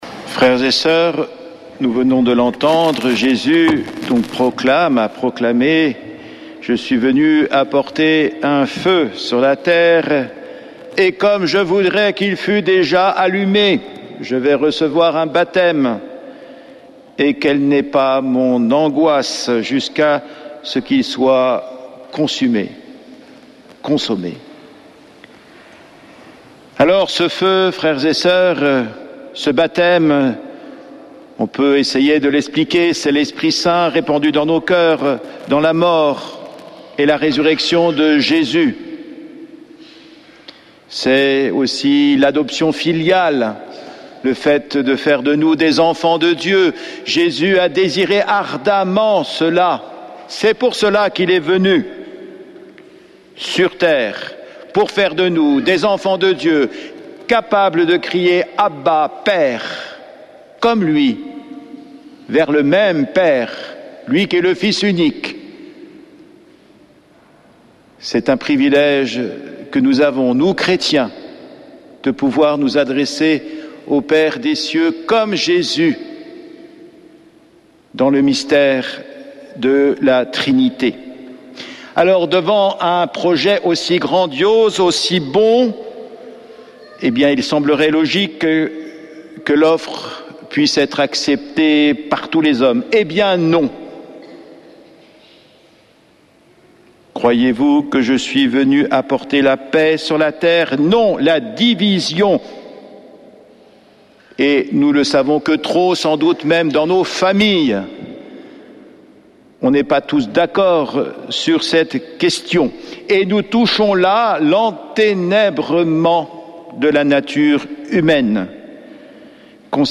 Homélie depuis le couvent des Dominicains de Toulouse du 17 août